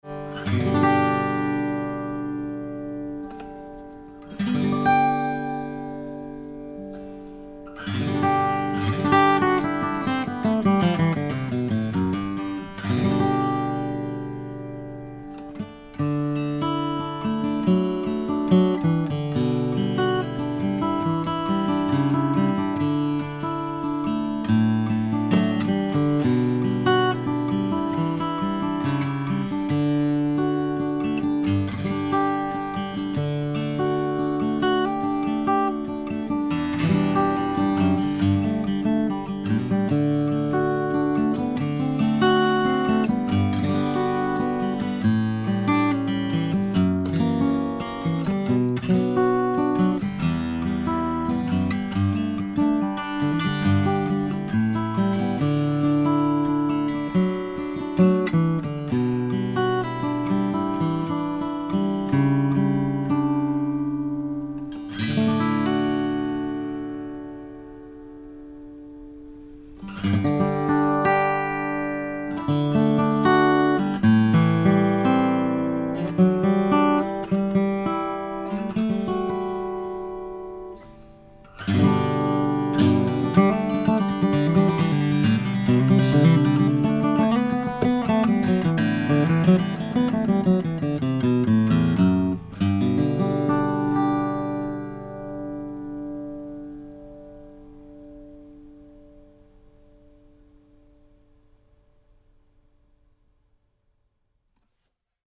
Taylor's Grand Concert acoustic guitars have captivated many players with their precise, articulate sound and comfortably compact dimensions.
At the heart of the 112ce-S lies a solid spruce top, a distinguished feature that imparts a bold, punchy sound, echoing with balanced and clean overtones.
Taylor-112ce-S.mp3